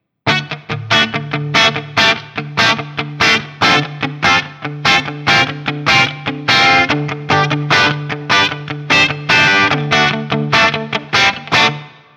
I recorded this guitar using my Axe-FX II XL+, direct into my Macbook Pro using Audacity.
Since there is only one pickup and thus no pickup selector switch, the recordings are each of the one pickup with the knobs on 10.